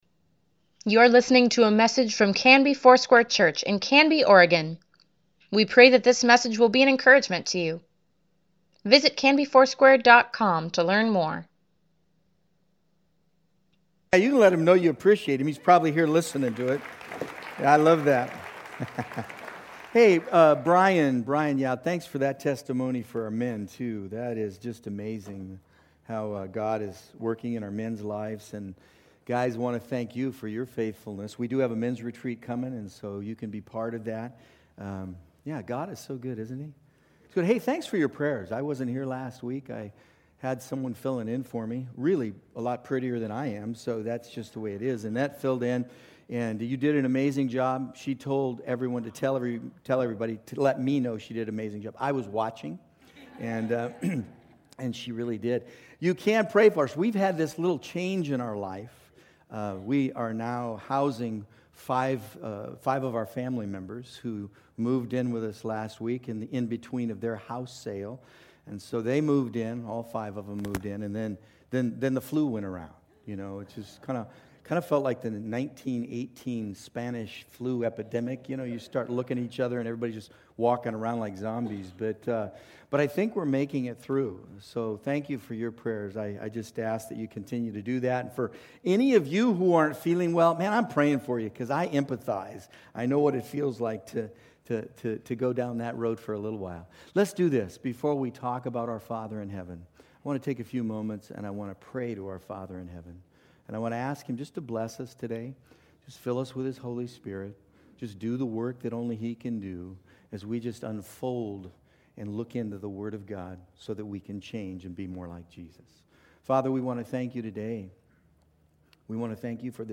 Weekly Email Water Baptism Prayer Events Sermons Give Care for Carus Daniel March 31, 2019